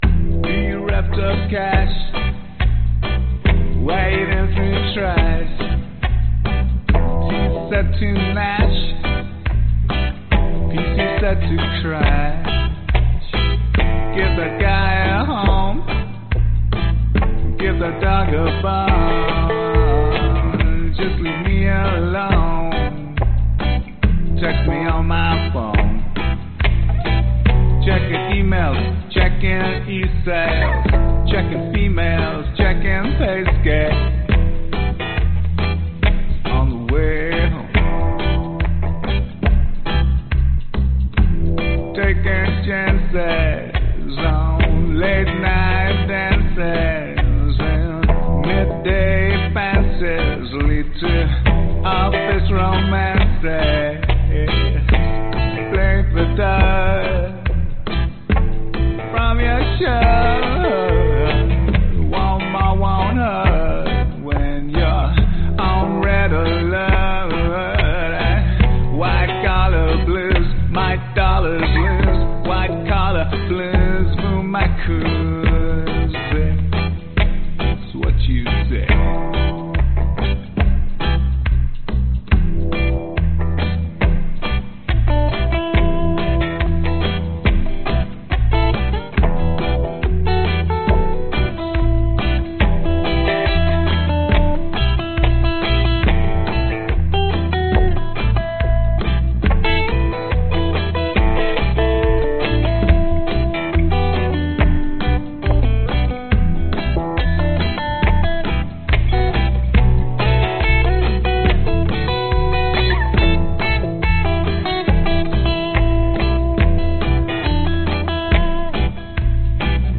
E min
吉他 原声 蓝调 雷鬼 配音 低音 男声 人声